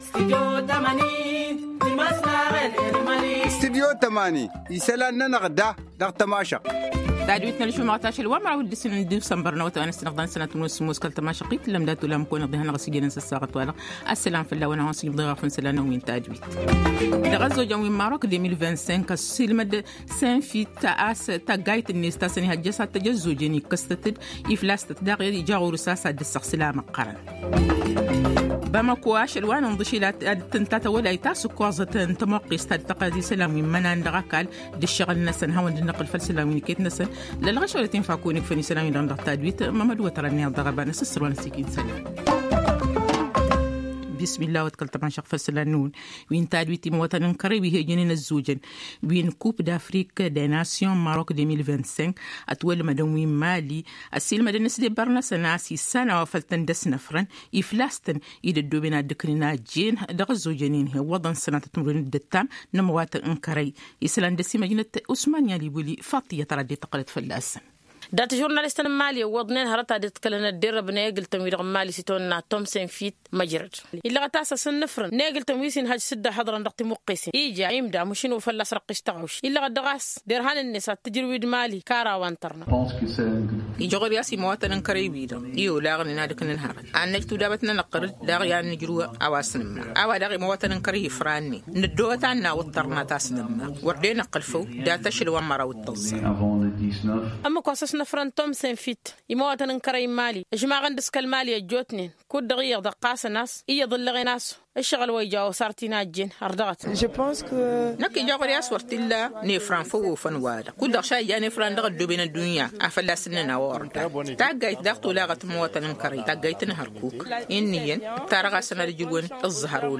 Le journal en Tamasheq du 12 décembre 2025